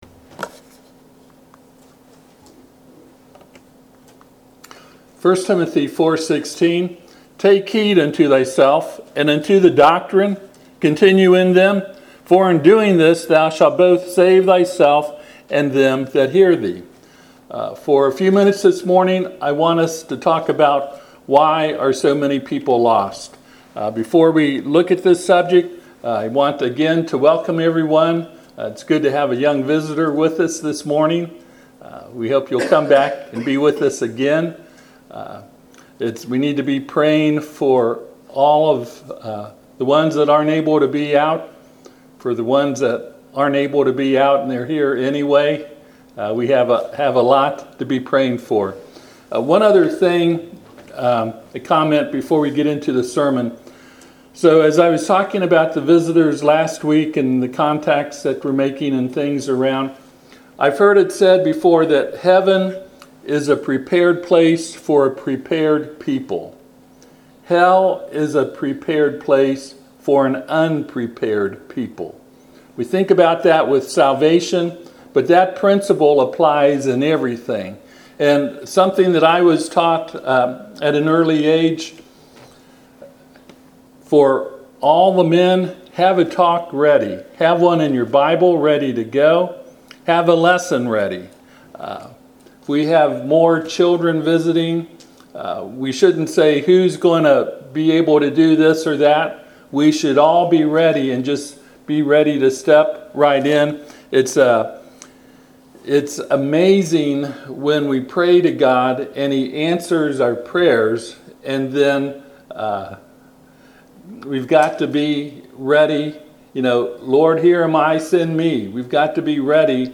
1 Timothy 4:16 Service Type: Sunday AM https